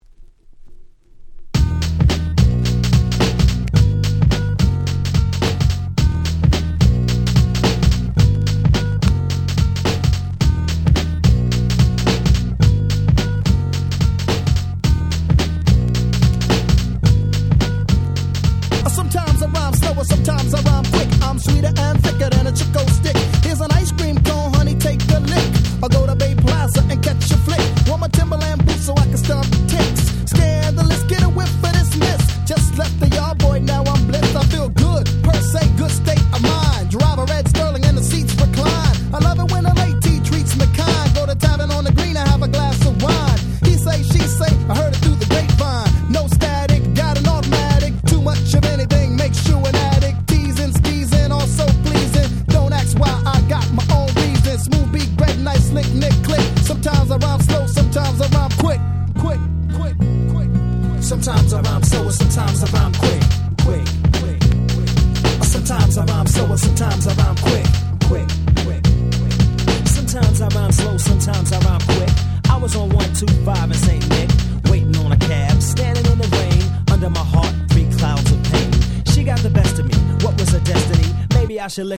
本盤は90'sの人気Hip Hop Classicsばかりを全6曲収録！！